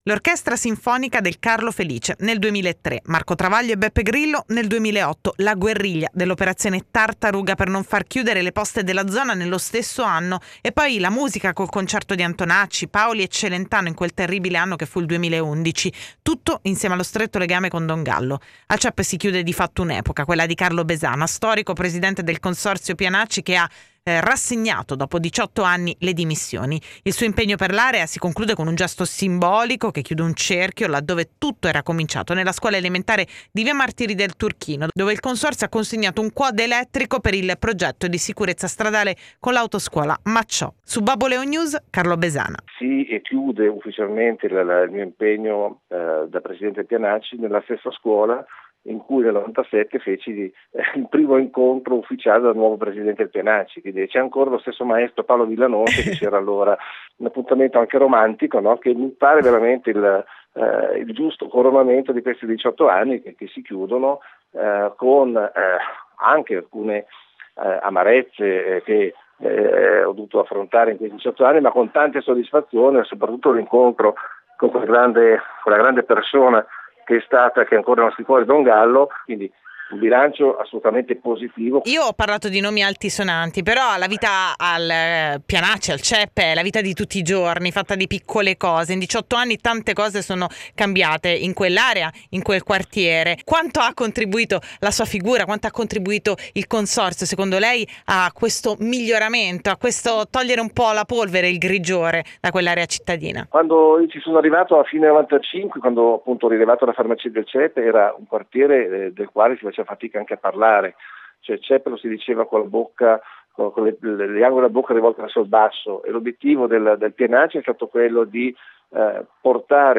(audio/intervista)